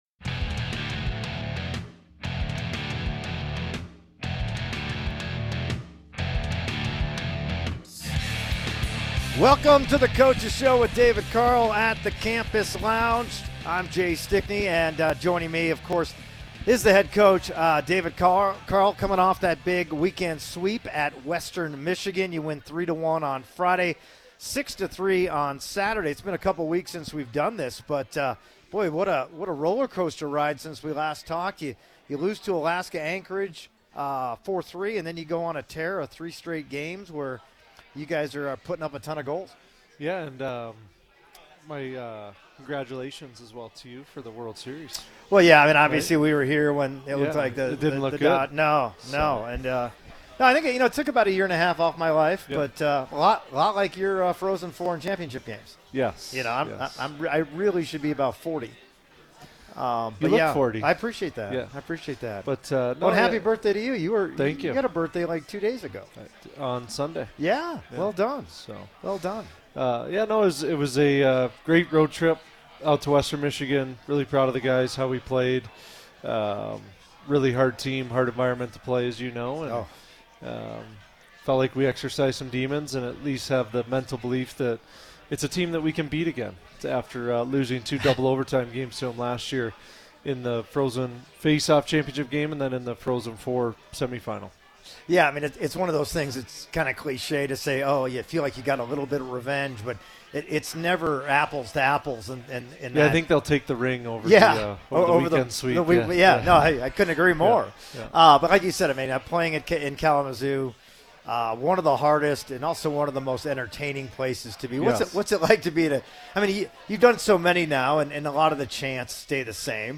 at the Campus Lounge to talk about the key road sweep at Western Michigan and previews the Gold Pan matchup with Colorado College.